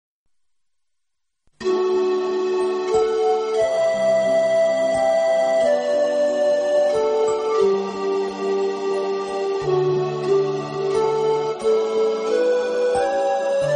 这丝丝情意像恋人般彼此依偎，轻轻地，静静地直到永远！